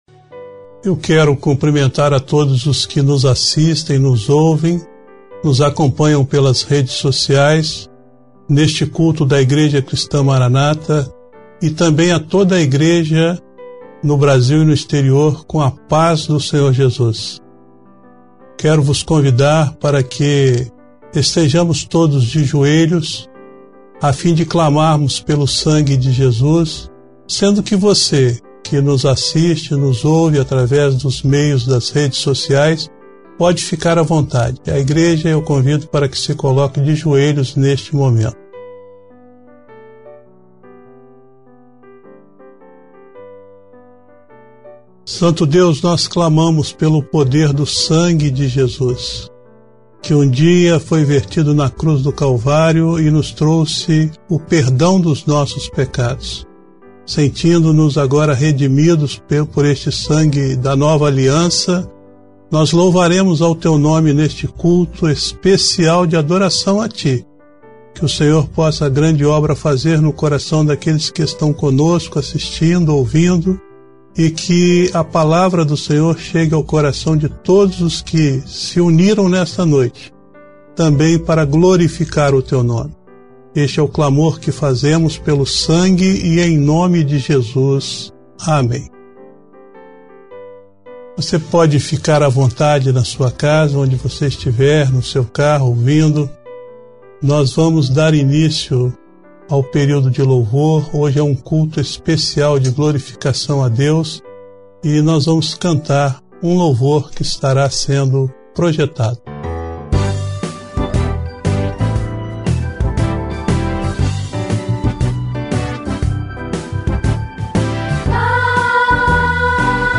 Culto online transmitido na segunda-feira, dia 24 de agosto de 2020